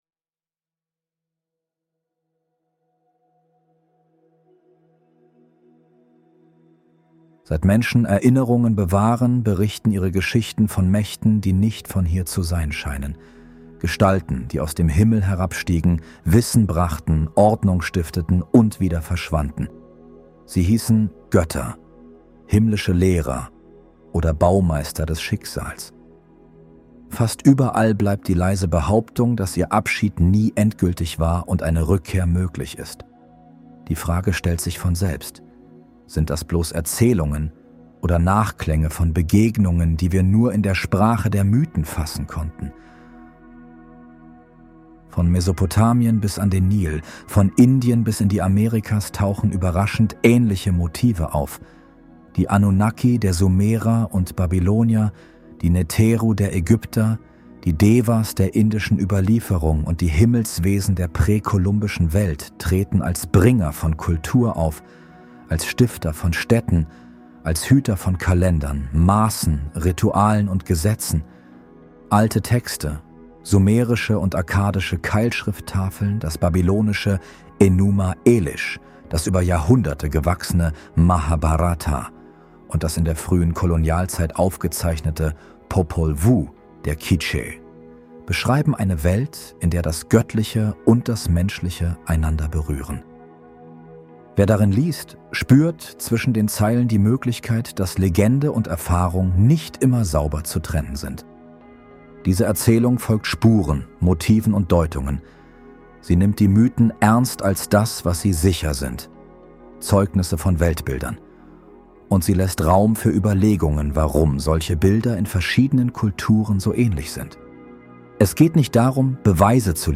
Urgötter-Geheimnisse - Diese nächtlichen Wahrheiten verändern alles (Hörbuch)